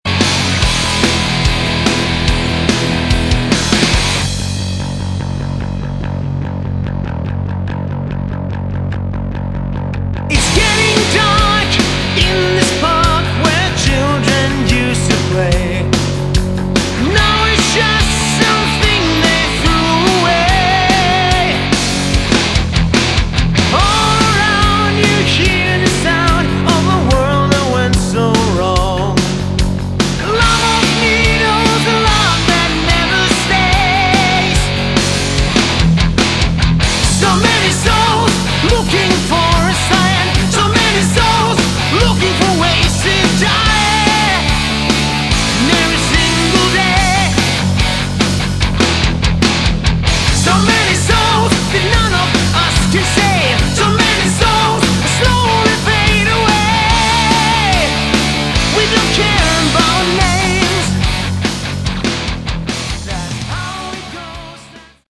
Category: Melodic Metal
lead vocals
guitars, backing vocals
bass, backing vocals
drums